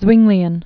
(zwĭnglē-ən, swĭng-, tsvĭng-)